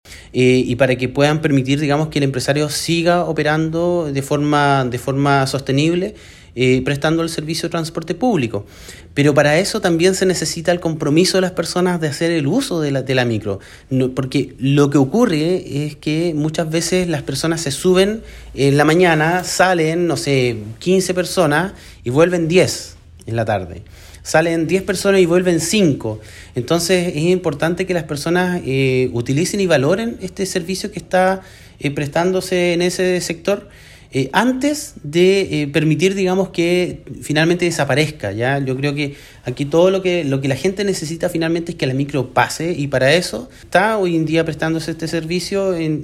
El representante regional de la cartera de transportes hizo un llamado a los usuarios a utilizar el servicio público en esta zona rural, para evitar que en algún momento dadas las condiciones actuales el empresario decida terminar con este recorrido.